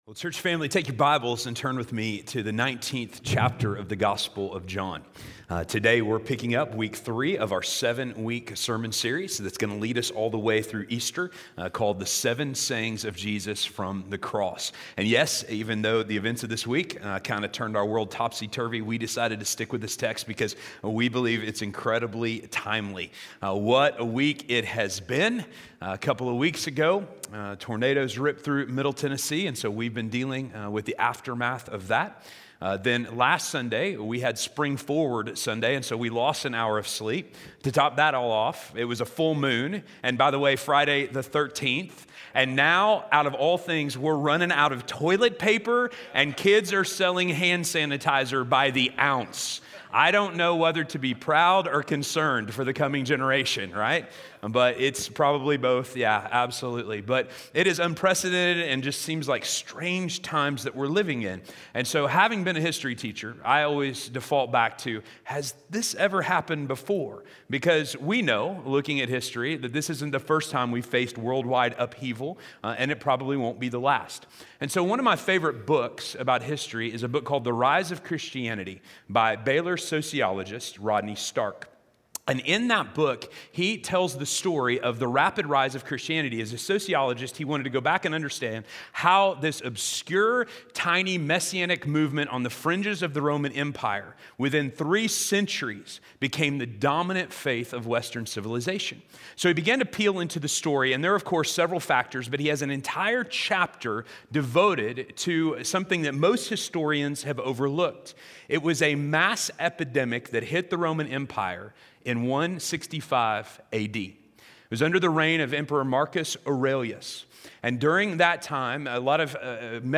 Woman Behold Your Son - Sermon - West Franklin